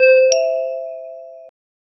Natural, close-mic perspective 0:10 The sound of a professionally recorded 10 inch maple rack tom drum with no effects or reverb hit with a wood tip drum stick directly in the center. 0:10 Short low chime with warm tone, secure and calm, subtle resonance, modern digital lock sound, clean and dry 0:02
short-low-chime-with-warm-hmtotn3t.wav